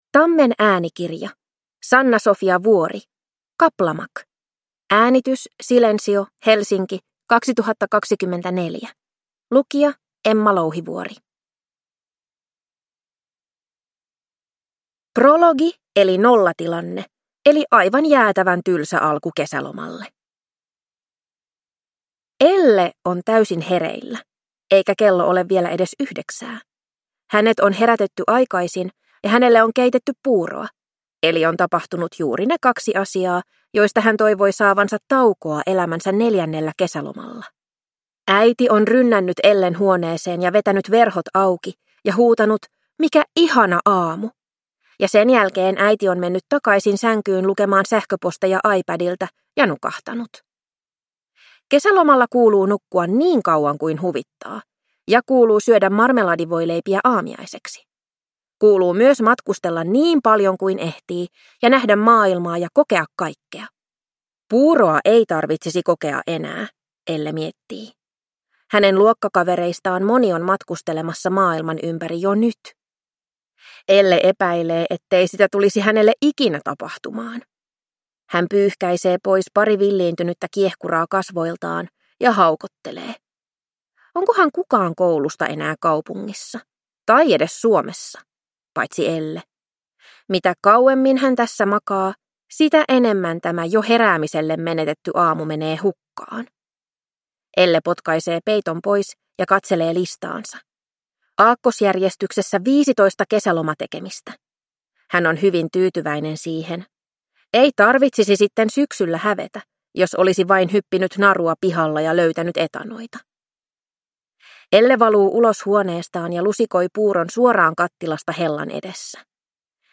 Kaplamak – Ljudbok